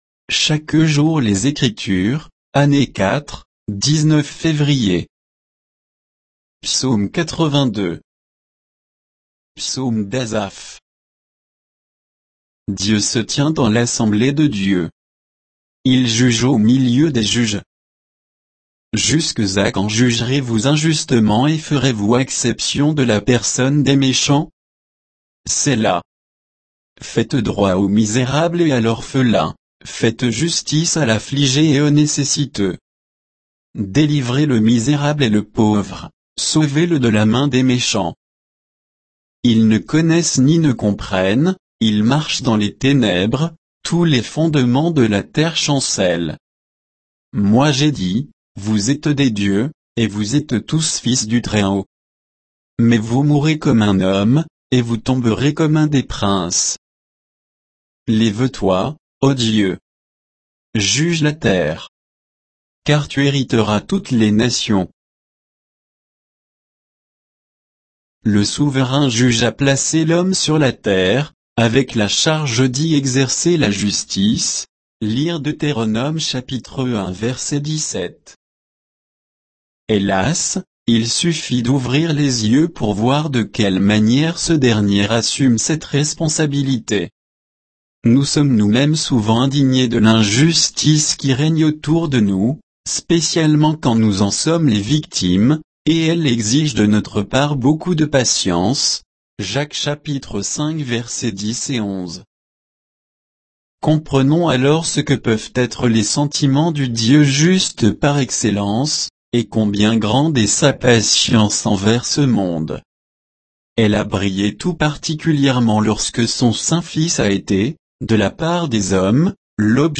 Méditation quoditienne de Chaque jour les Écritures sur Psaume 82